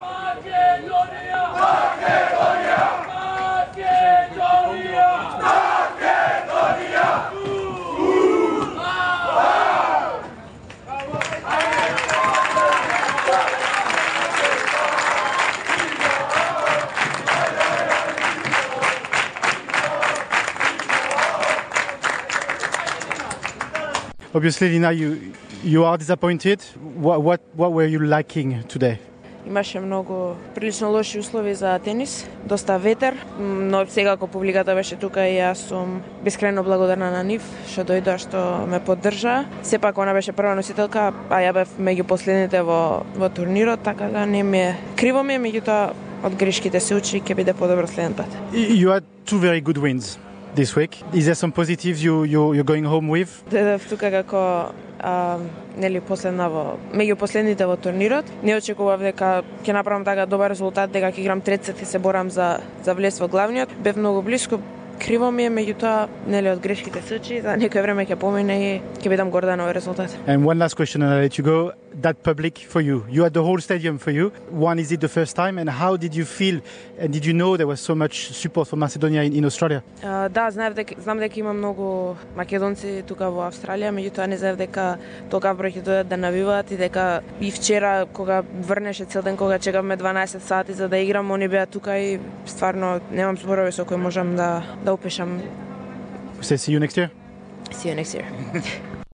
Lina Gjorcheska’s exclusive farewell interview for SBS : Huge thank you to the fans, see you next year!